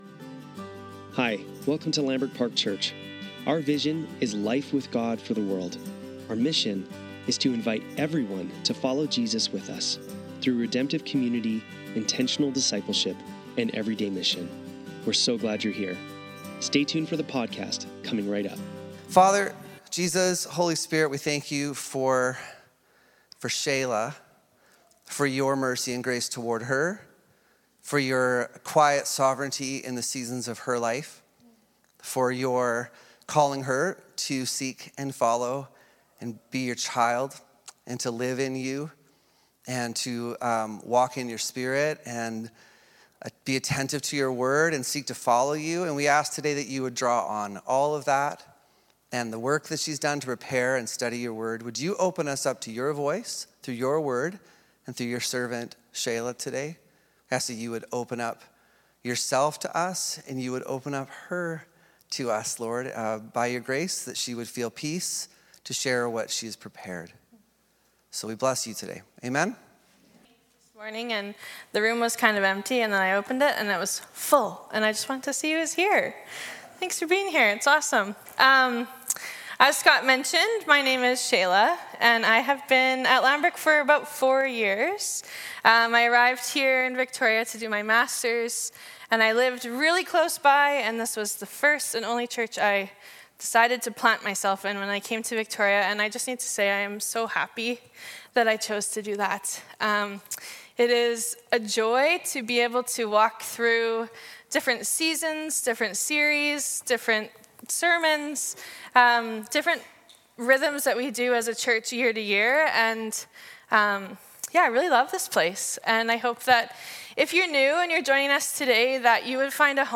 Sunday Service - January 26, 2025